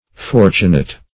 Fortunate \For"tu*nate\ (?; 135), a. [L. fortunatus, p. p. of